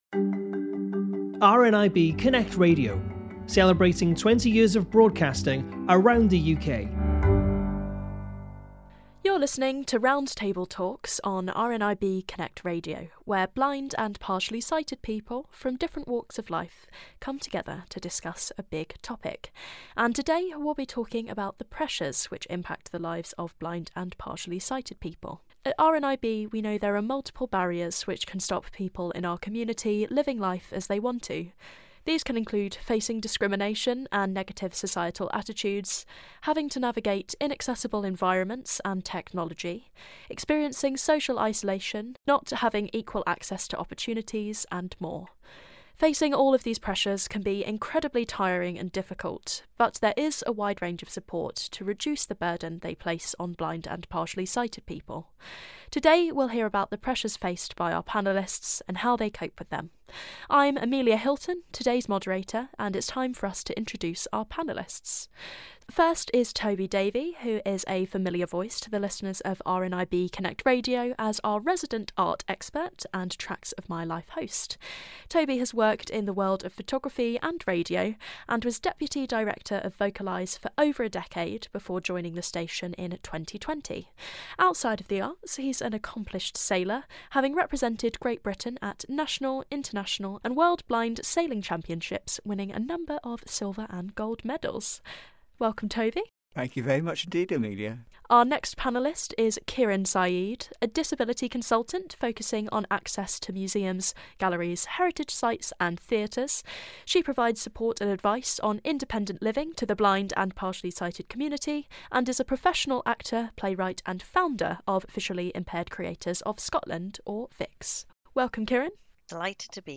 Pressures Faced by Blind and Partially Sighted People - 20th Anniversary Roundtable